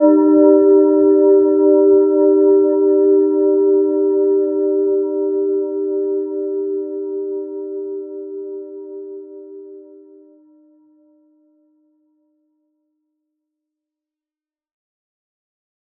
Gentle-Metallic-2-G4-p.wav